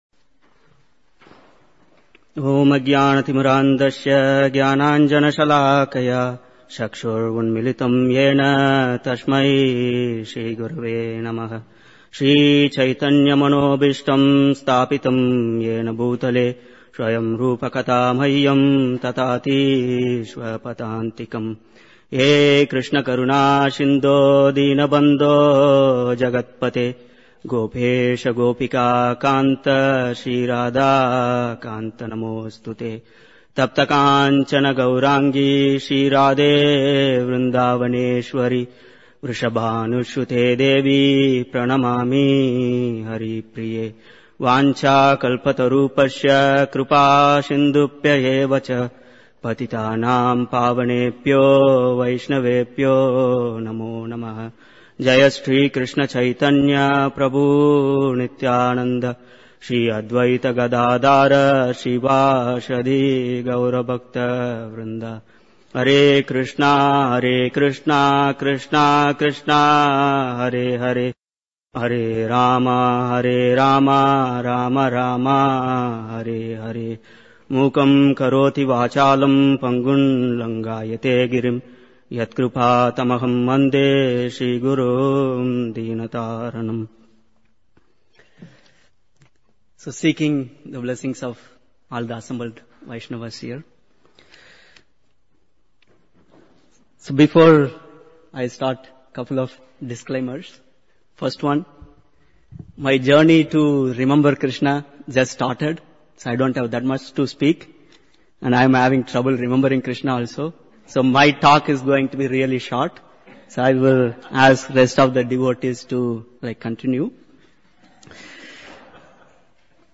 A6–My Journey into the Realm of Remembering My Forgotten Krsna by Various Devotees – New Year Retreat Chicago December 2017